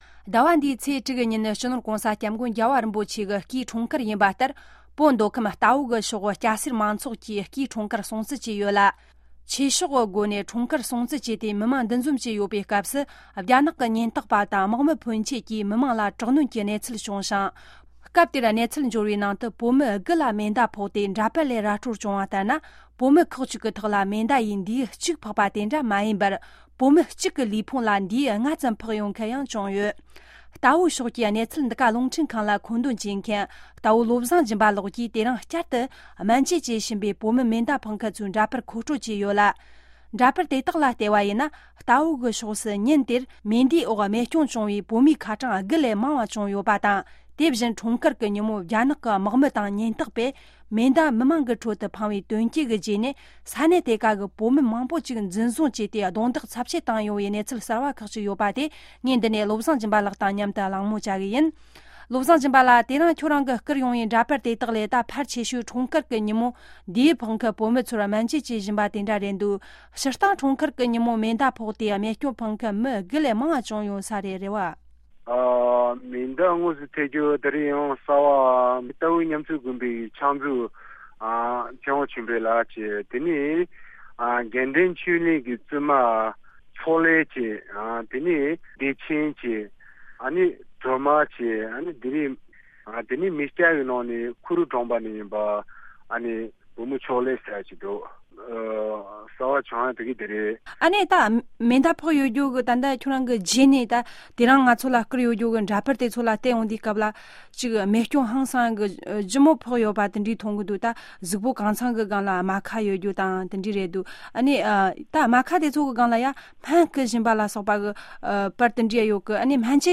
གནས་འདྲི་བྱས་ཡོད།